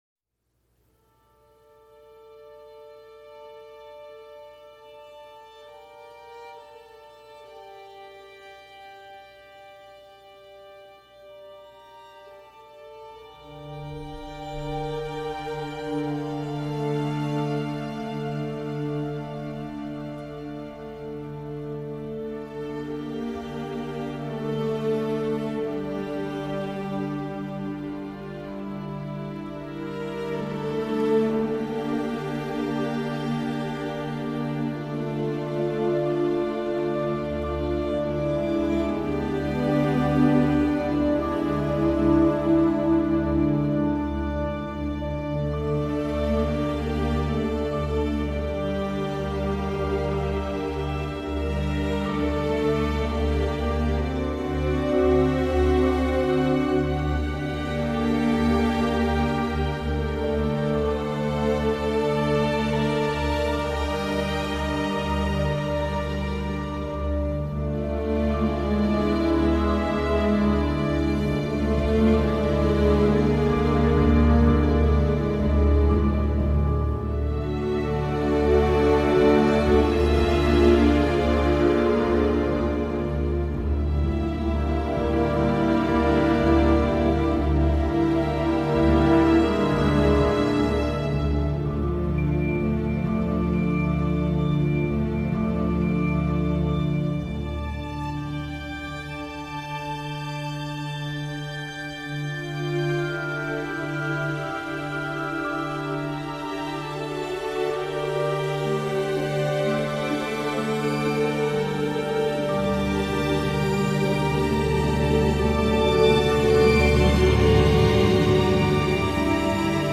véritable toile d’araignée dominée par des cordes vénéneuses